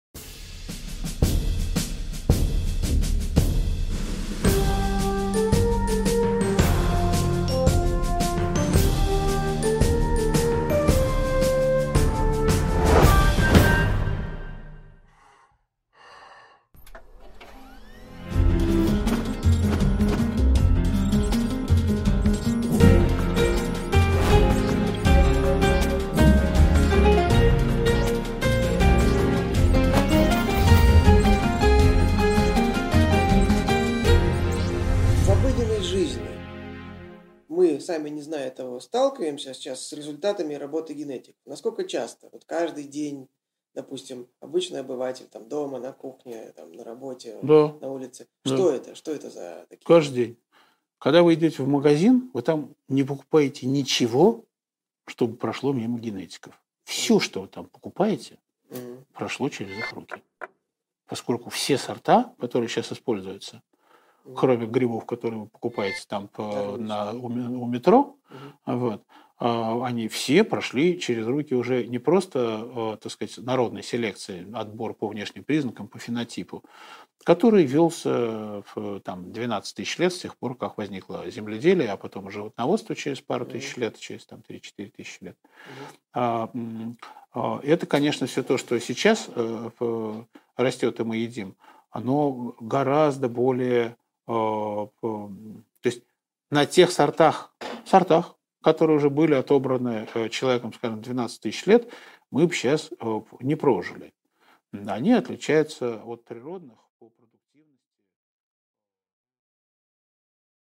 Аудиокнига Генетика в нашей жизни | Библиотека аудиокниг